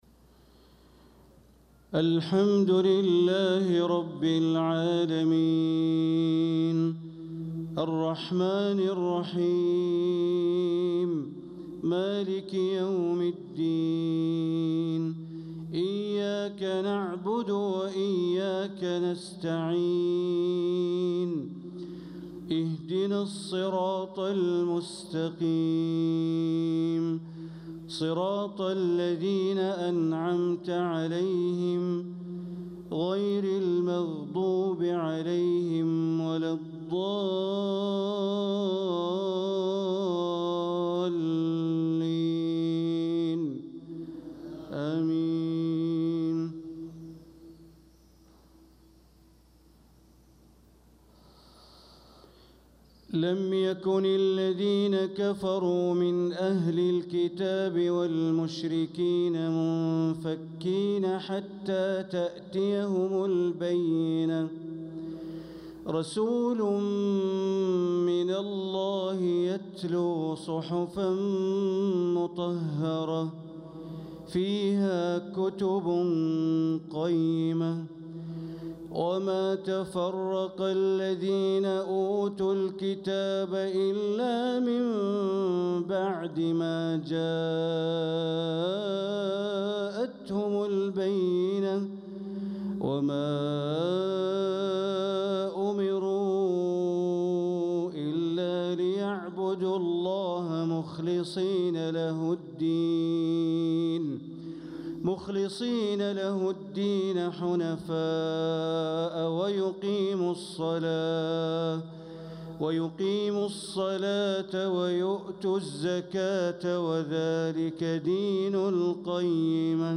صلاة المغرب للقارئ بندر بليلة 3 صفر 1446 هـ
تِلَاوَات الْحَرَمَيْن .